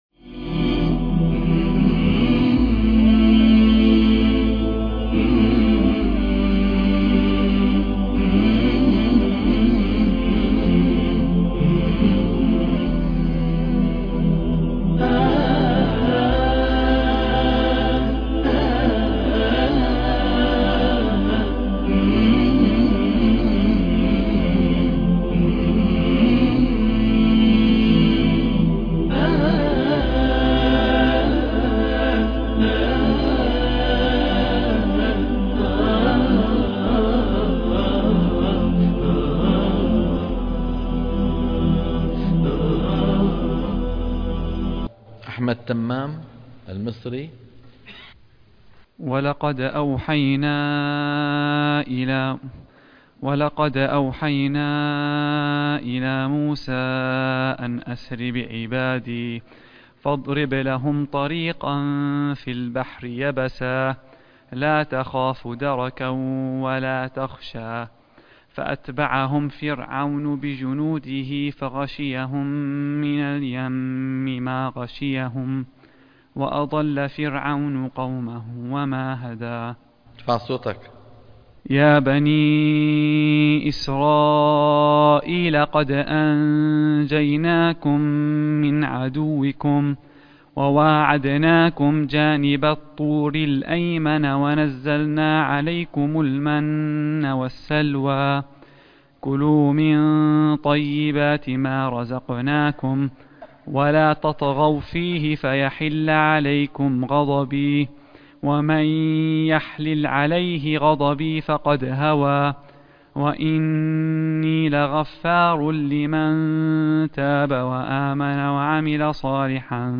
برنامج تصحيح التلاوة الحلقة - 94 - تصحيح التلاوة من الصفحة 317 إلى 324 - الشيخ أيمن سويد